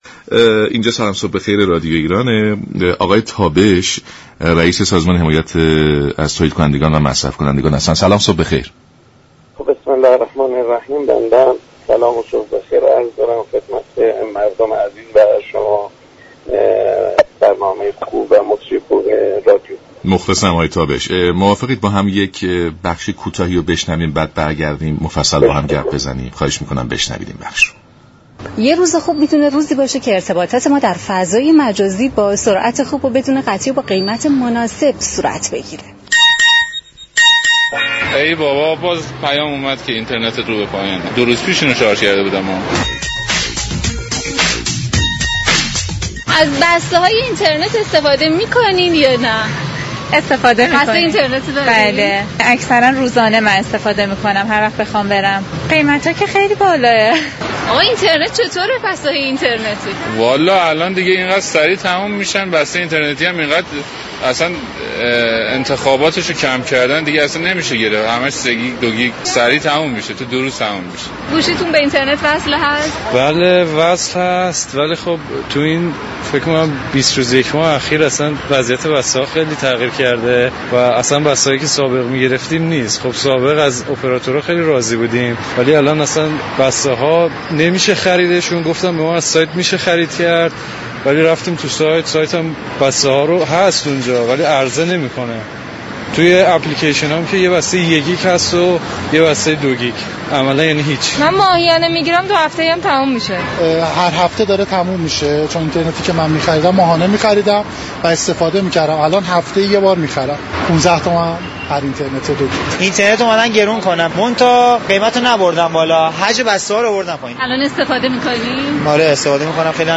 عباس تابش در رادیو ایران: در تلاش هستیم تعرفه بسته های اینترنتی به حالت قبل بازگردد
به گزارش شبكه رادیویی ایران، عباس تابش رییس سازمان حمایت از مصرف كنندگان و تولیدكنندگان در برنامه «سلام صبح بخیر» درباره گله مندی مردم از وضعیت بسته های اینترنتی كشور گفت: قیمت بسته های اینترنتی تغییر نكرده است و تنها حجم بسته هایی كه بیشتر مورد استفاده قرار می گیرد كاهش یافته است.